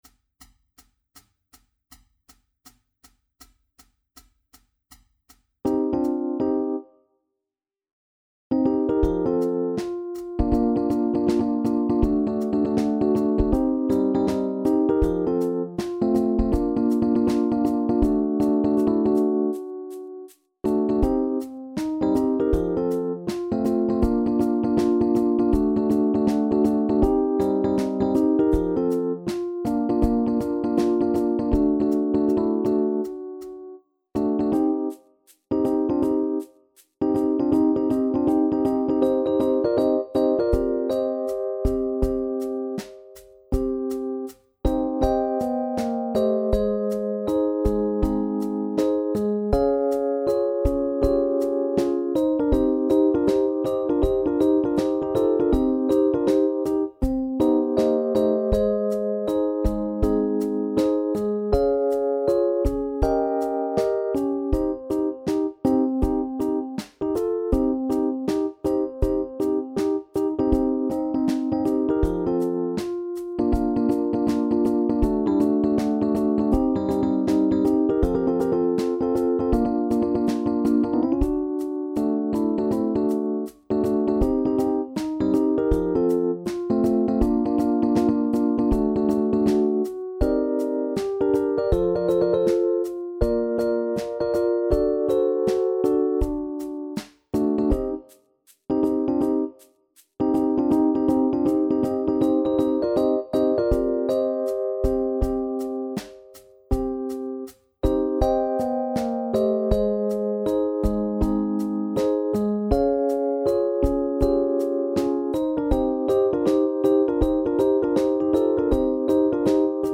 SSSAA